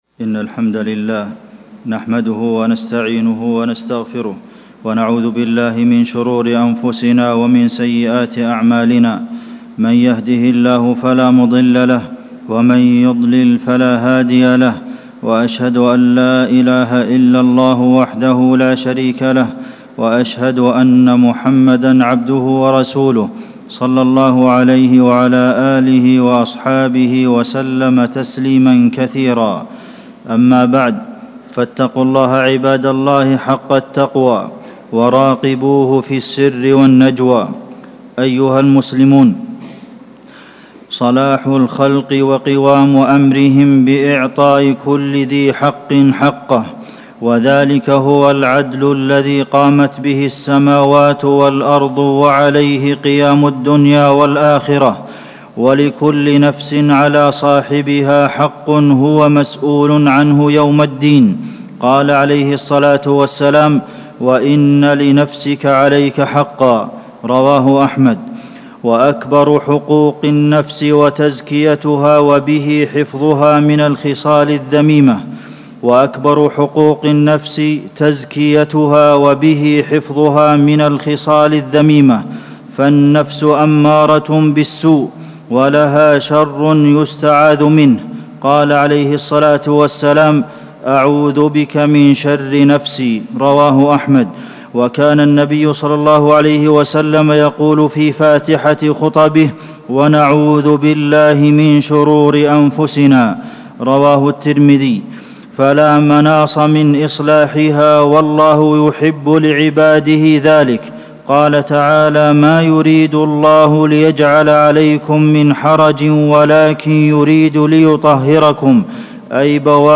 تاريخ النشر ١٥ شوال ١٤٣٩ هـ المكان: المسجد النبوي الشيخ: فضيلة الشيخ د. عبدالمحسن بن محمد القاسم فضيلة الشيخ د. عبدالمحسن بن محمد القاسم تزكية النفس